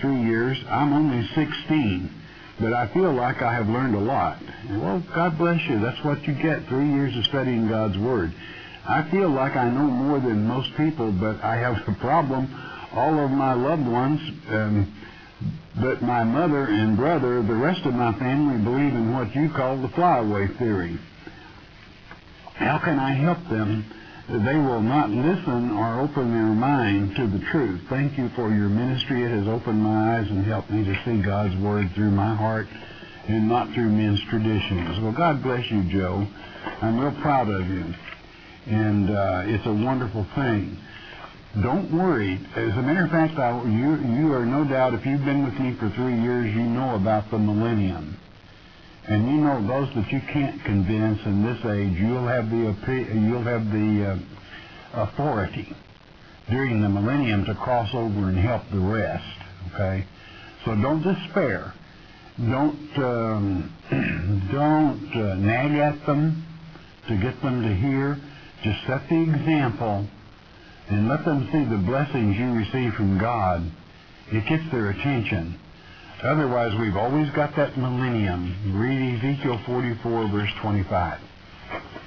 Click for 2 minute Audio-Bite  (SCN television broadcast Feb 3, 2006)